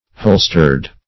Holstered \Hol"stered\, a. Bearing holsters.